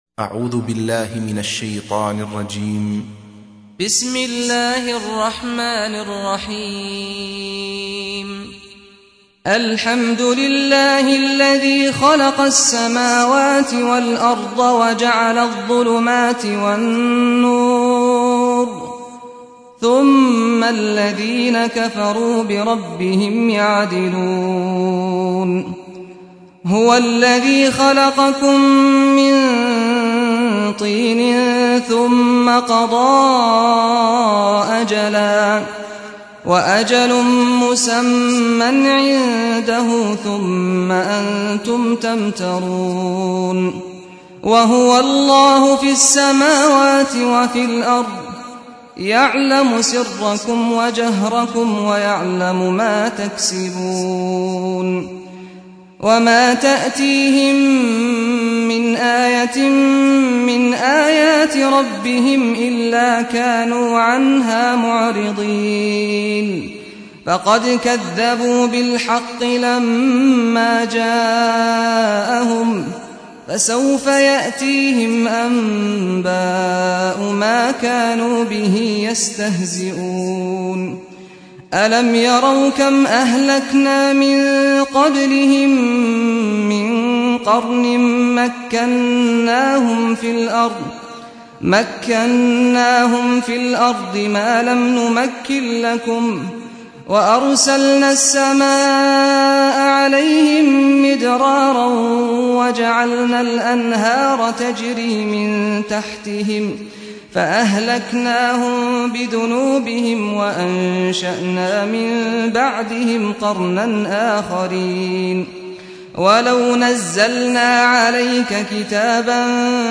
سُورَةُ الأَنۡعَامِ بصوت الشيخ سعد الغامدي